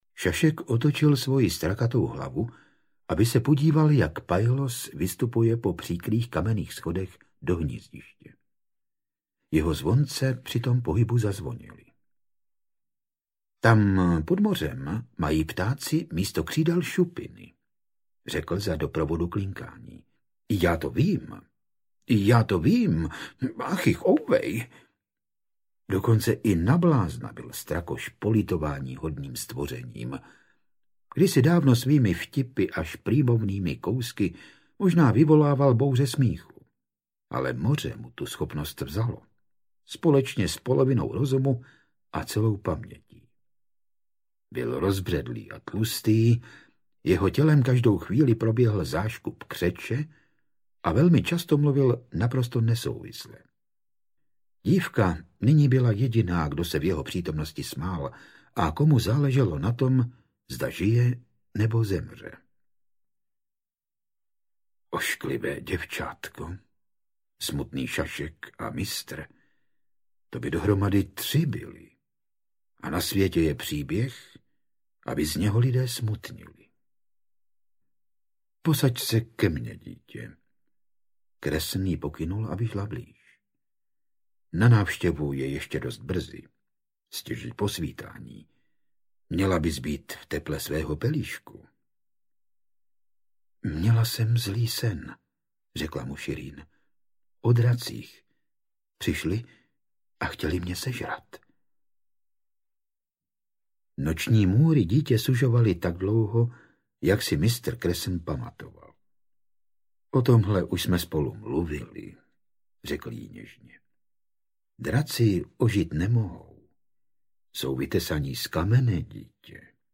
Střet králů audiokniha
Ukázka z knihy
• InterpretFrantišek Dočkal